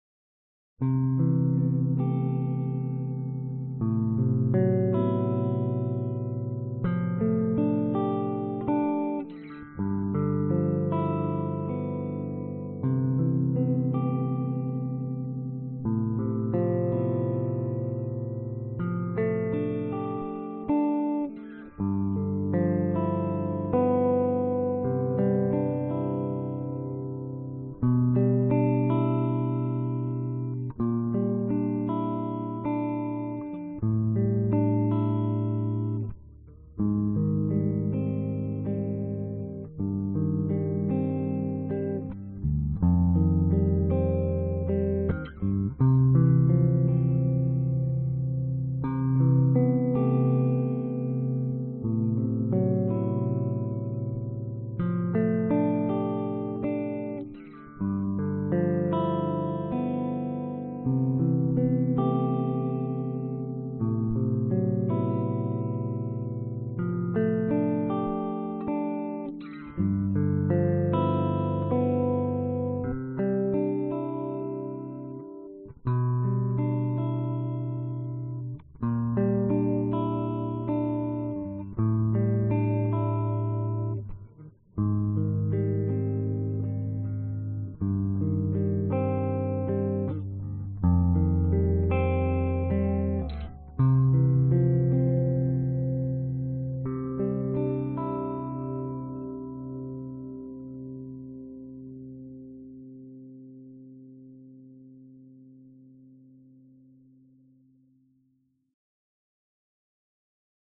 描述：吉他和弦、旋律、音量踏板和即兴演奏。将添加主音。
标签： 吉他 器乐 寒冷 忧伤 电影音乐 视频音乐 原声 民间音乐
声道立体声